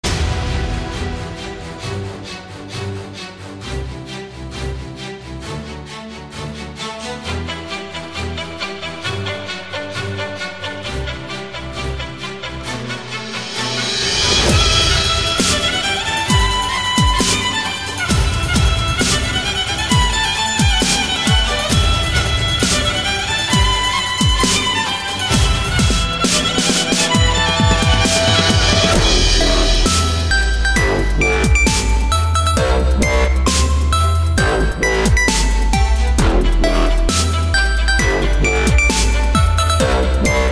Стиль: Dub & Dubstep